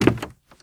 High Quality Footsteps
Wood, Creaky
STEPS Wood, Creaky, Walk 09.wav